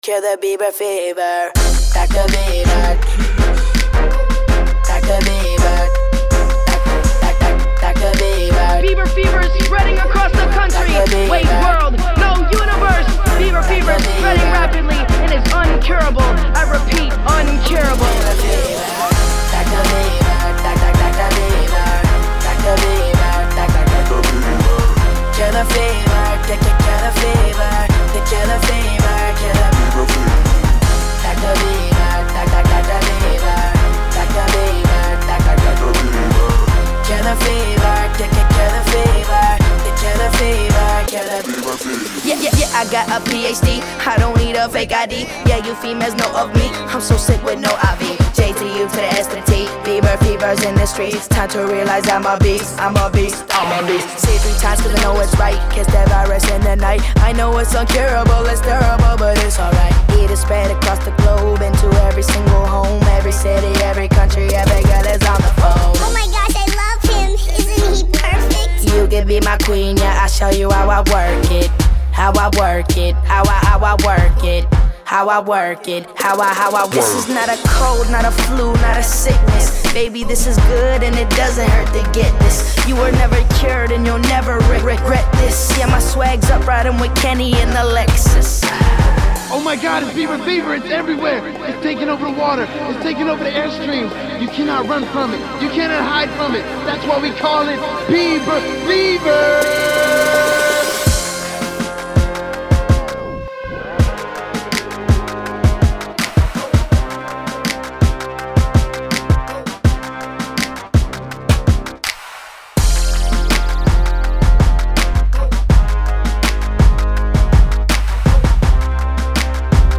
Категория: Попсовые песни